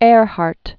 (ârhärt), Amelia 1897?-1937.